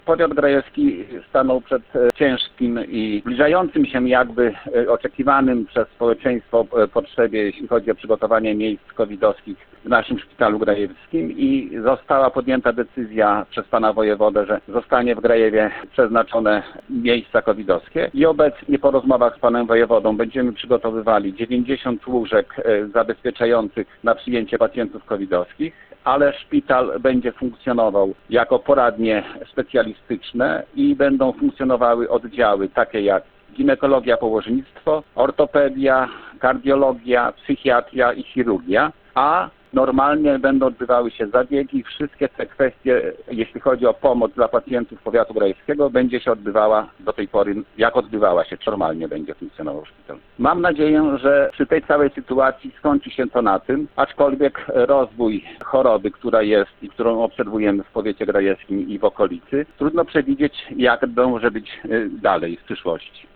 Starosta powiatu grajewskiego Waldemar Remfeld powiedział 4 listopada rano, że są już po rozmowach z wojewodą i szpital w Grajewie nie zostanie przekształcony w covidowy.